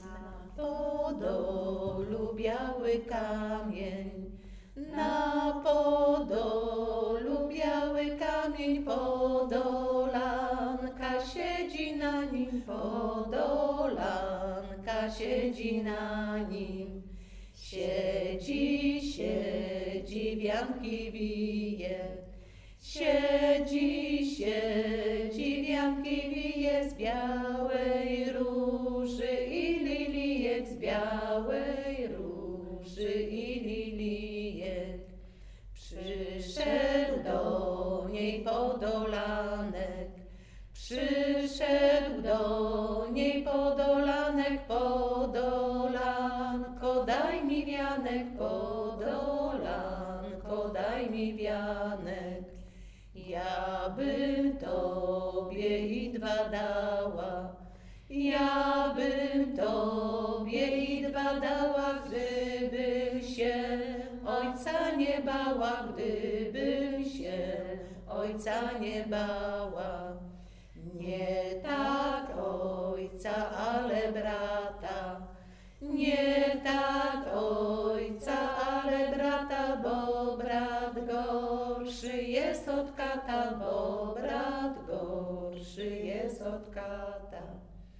Pieśń "Na Podolu biały kamień", Podolanki – strona dokumentu
Nagranie wykonano podczas próby zespołu.